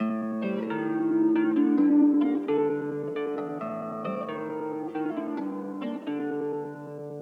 guitare_02_133.wav